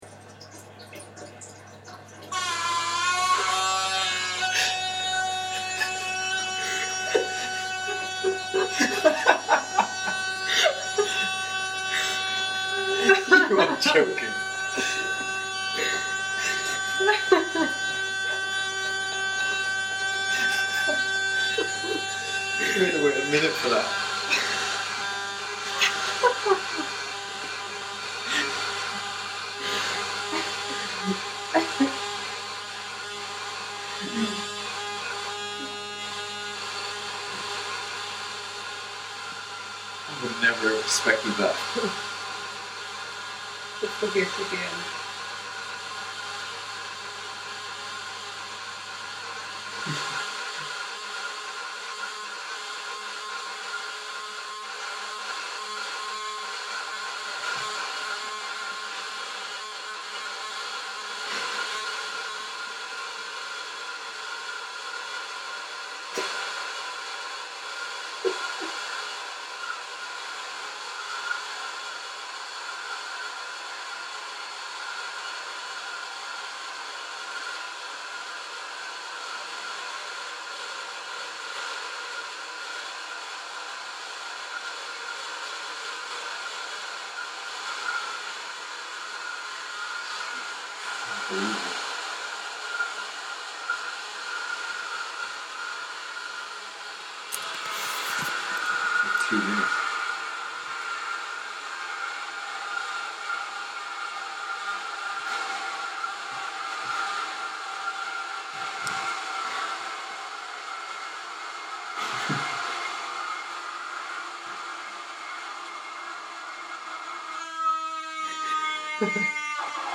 I was staying in a really decrepit stately home in Ireland, the place is fantastic for souvenirs everything comes away in ya hand!
I recorded the toilet cistern in my room, filling up, it’s nuts—totally nuts.
The end of the recording is best, the cistern knows it’s time is up, so it goes into a great crescendo of musical ability.